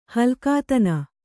♪ halkātana